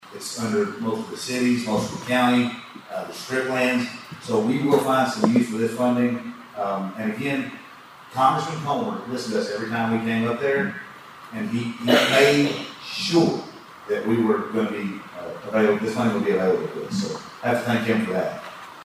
Local leaders are actively advocating in Frankfort and Washington, D.C., to secure funding for economic growth, veteran support, and infrastructure projects, which was one of the topics discussed at last week’s State of the Cities and County event.